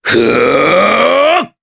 Nicx-Vox_Casting4_jp.wav